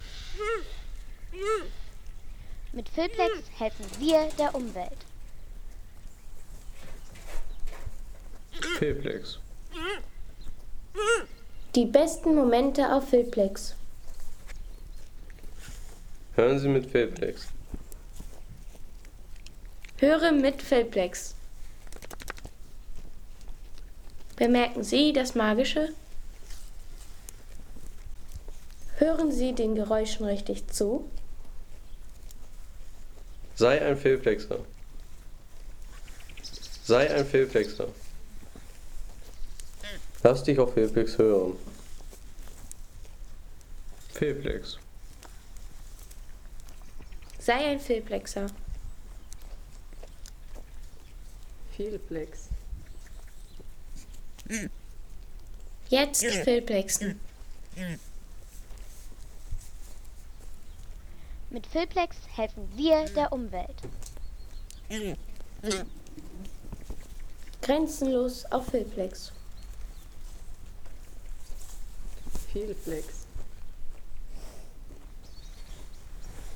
Rufe einer Rehkuh
Während der Fütterung wurden die sanften Rufe einer Rehkuh aufgenomm ... 3,50 € Inkl. 19% MwSt.